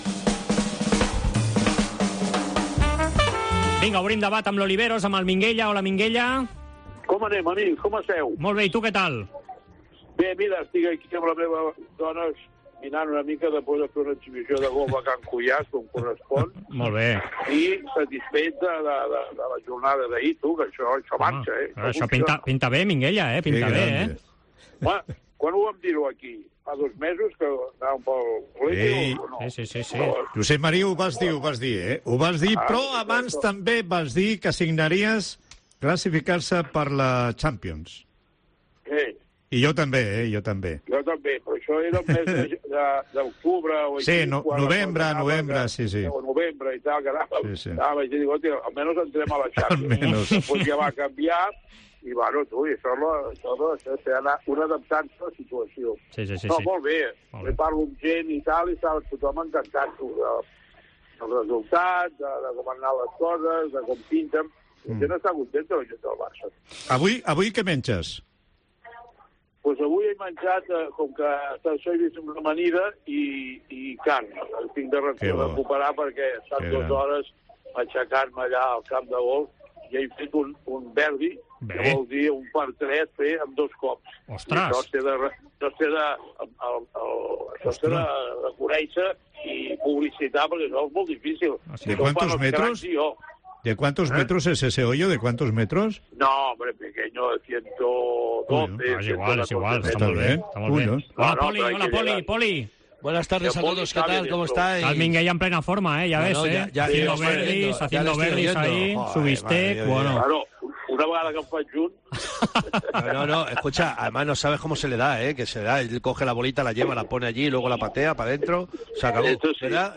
AUDIO: Escolta el 'Debat Esports COPE' amb Poli Rincón i Josep Maria Minguella analitzant com han canviat les coses a LaLiga pel conjunt de Ronald...